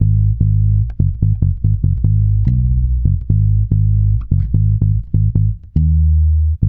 -MM RAGGA G#.wav